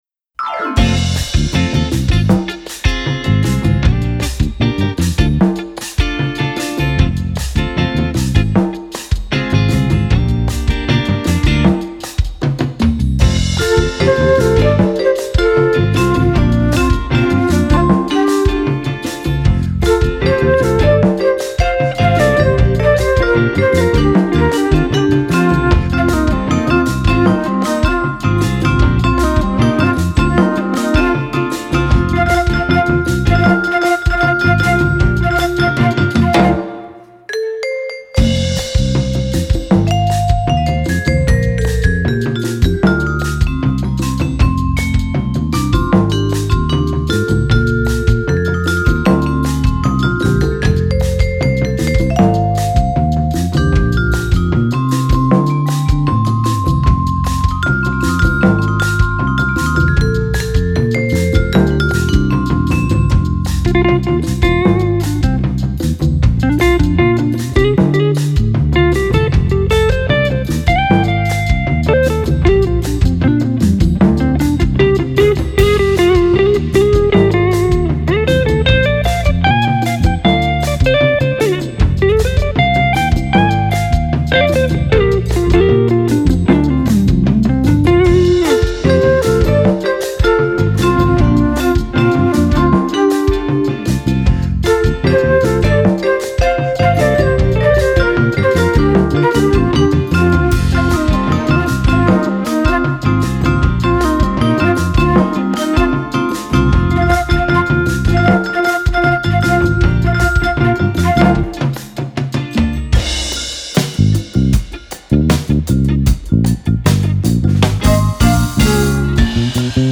Vibraphonist and multi-instrumentalist
File: Jazz